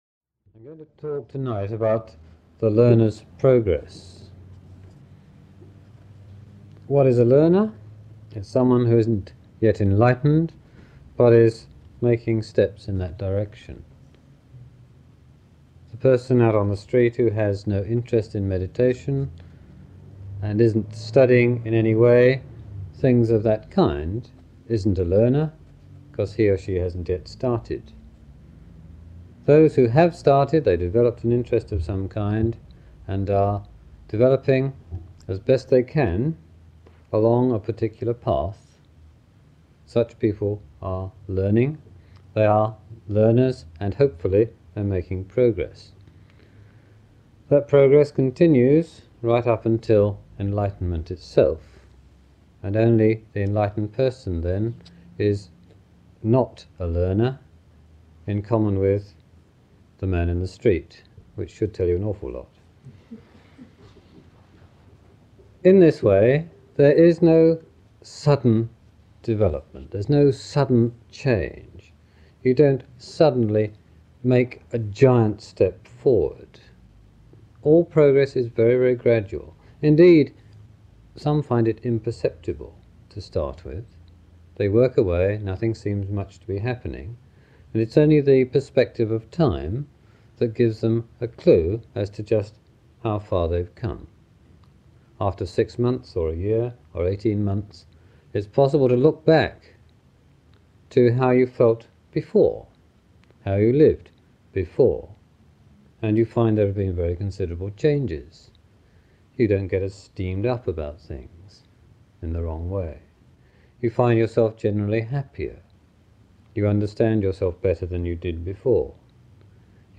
This talk was given in September 1985